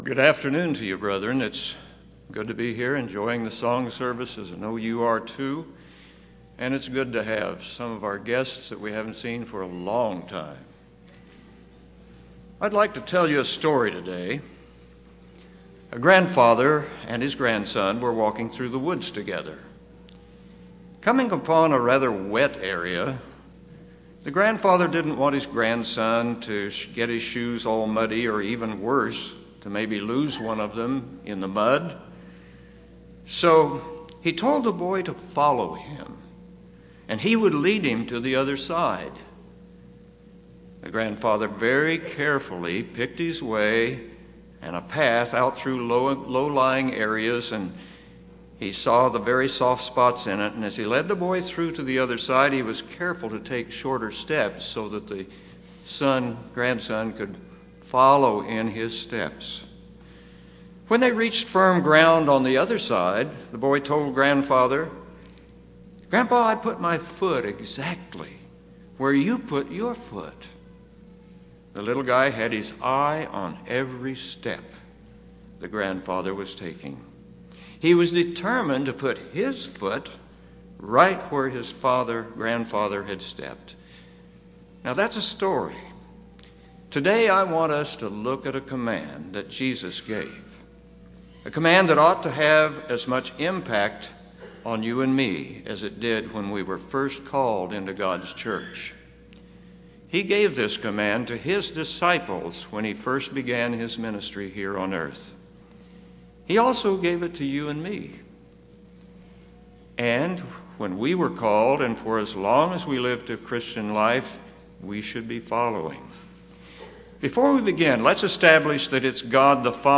UCG Sermon Notes These are the speaker’s notes.